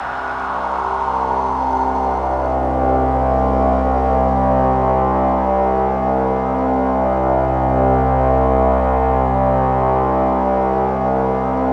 XXL 800 Pads